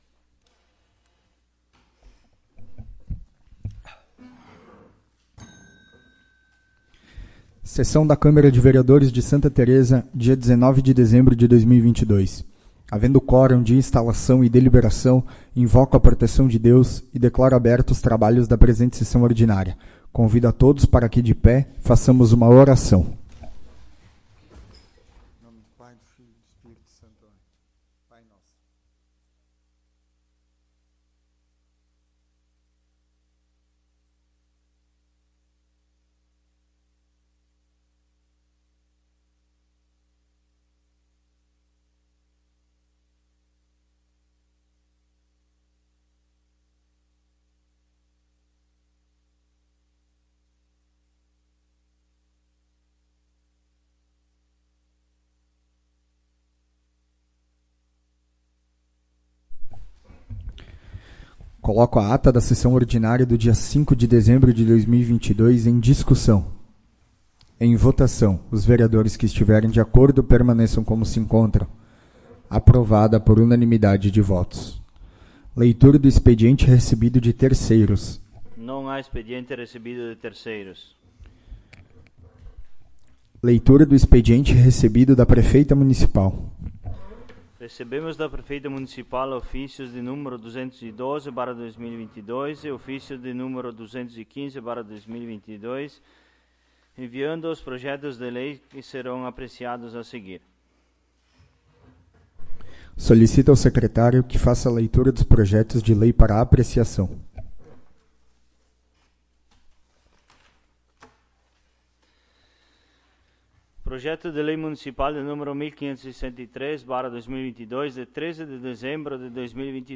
22° Sessão Ordinária de 2022
Local: Plenário Pedro Parenti